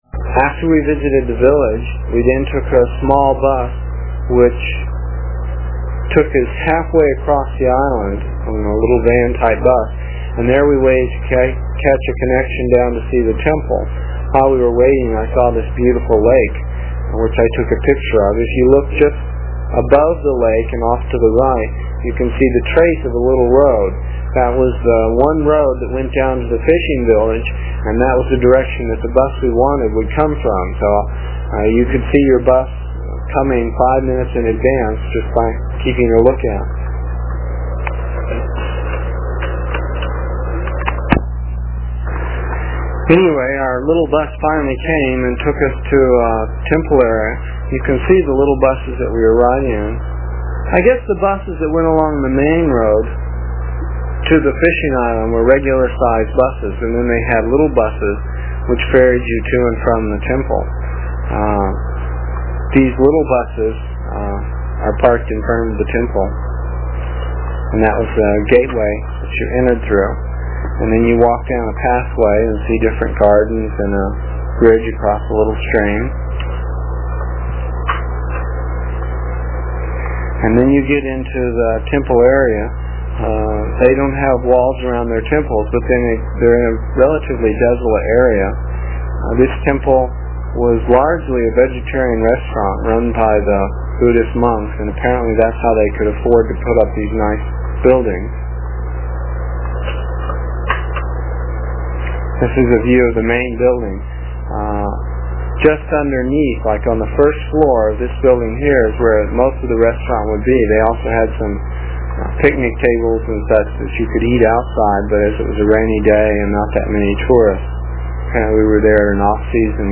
It is from the cassette tapes we made almost thirty years ago. I was pretty long winded (no rehearsals or editting and tapes were cheap) and the section for this page is about seven minutes and will take about three minutes to download with a dial up connection.